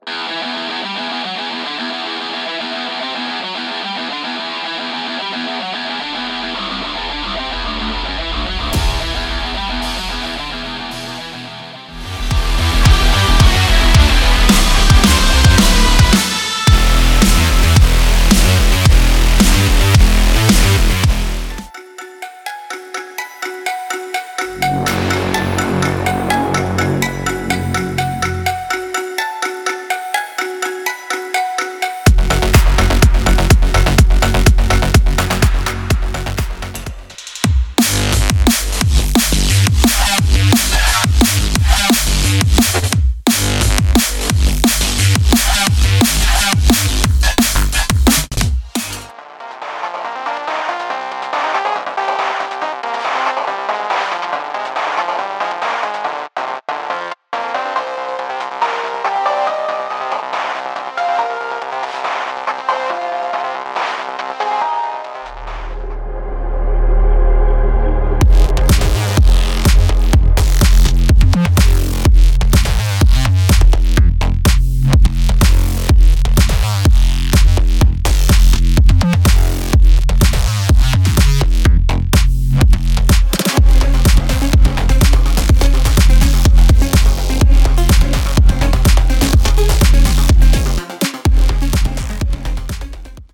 Soundtrack Snippet